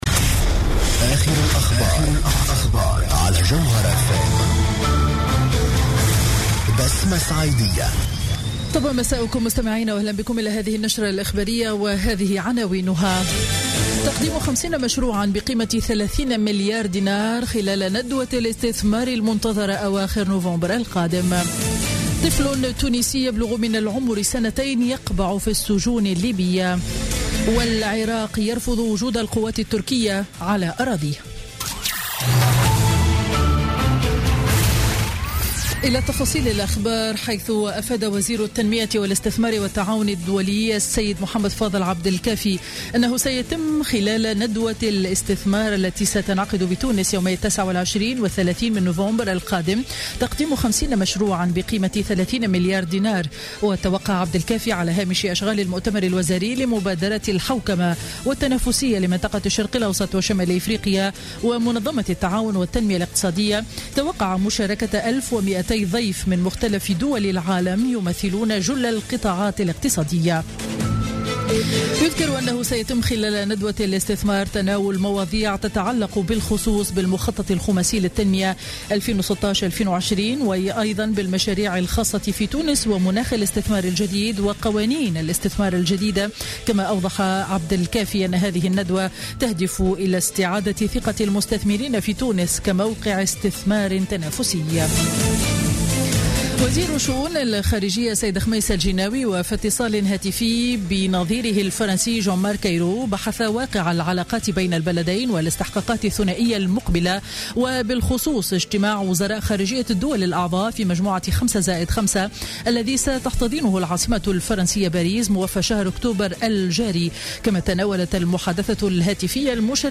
نشرة أخبار السابعة مساء ليوم الثلاثاء 4 أكتوبر 2016